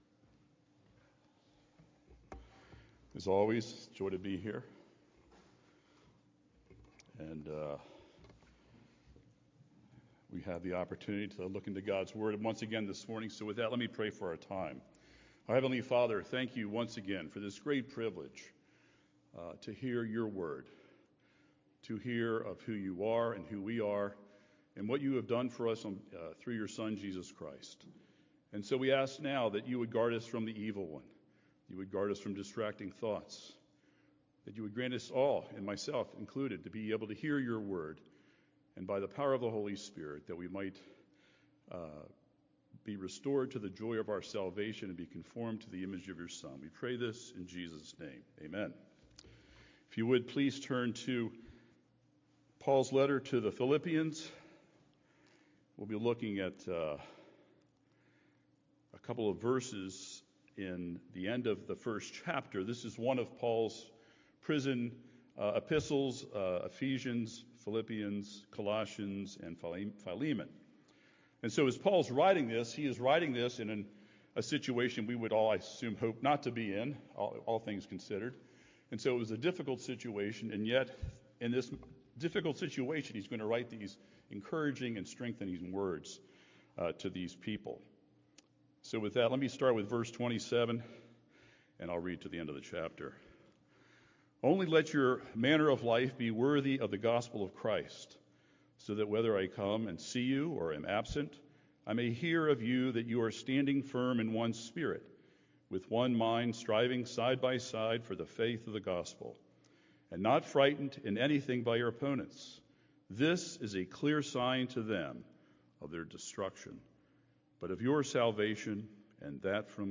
Suffering? Excuse Me ... Come Again?: Sermon on Philippians 1:18b-30 - New Hope Presbyterian Church